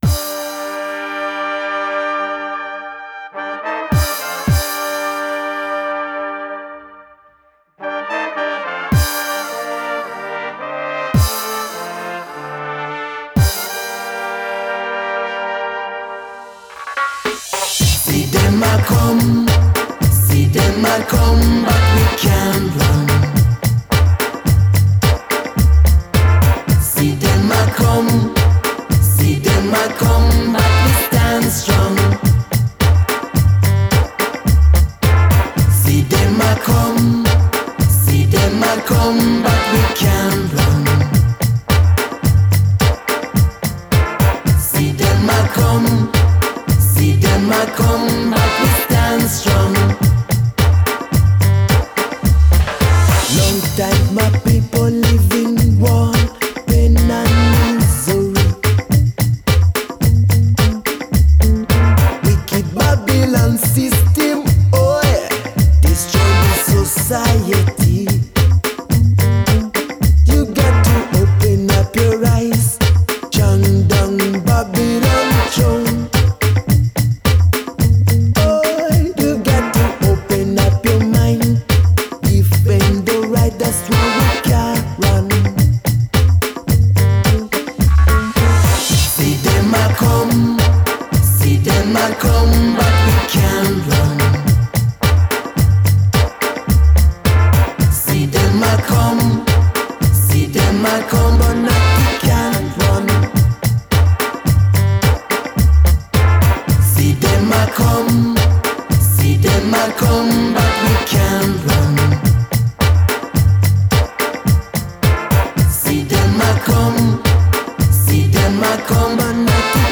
Genre: Downtempo, Chillout, World.